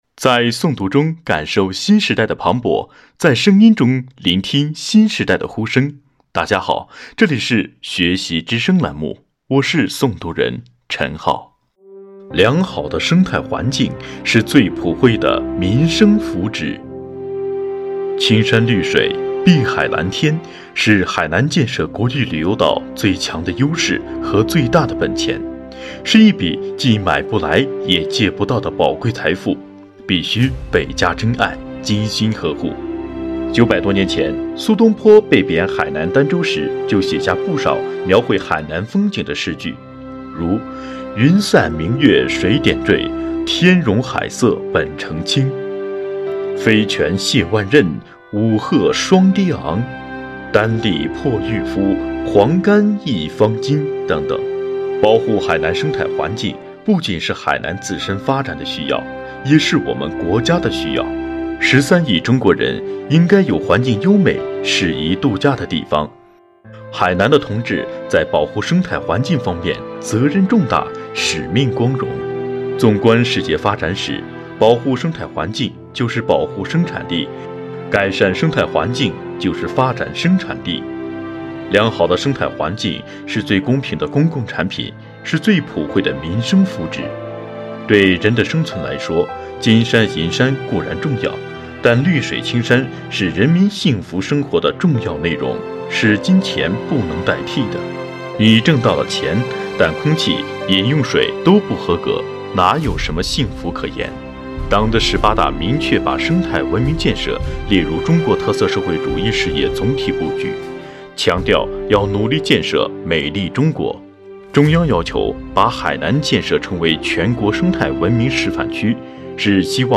学习之声丨《习近平著作选读》第一卷微诵读（四）